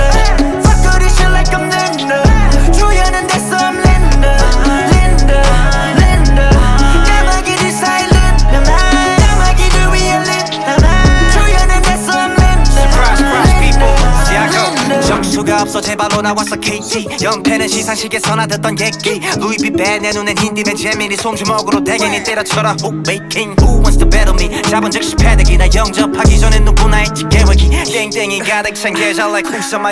Жанр: Рэп и хип-хоп
# Korean Hip-Hop